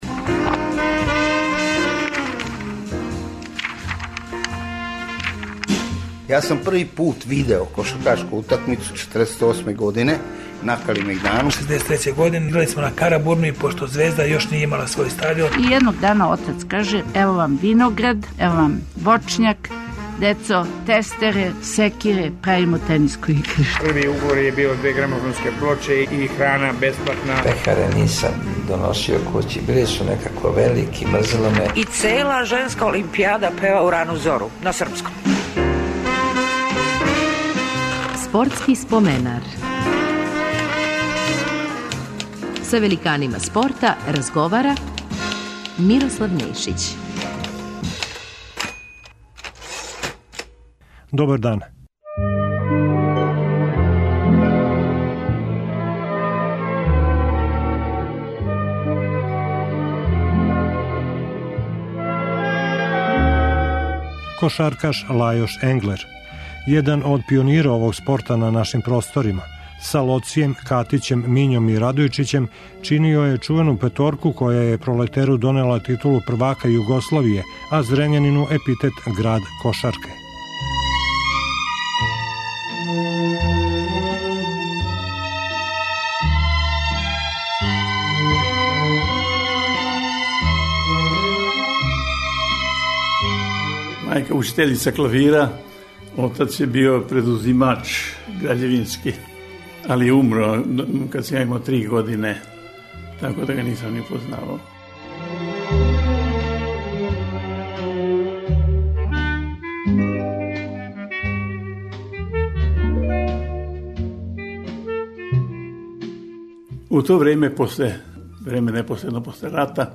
Ове недеље репризирамо разговор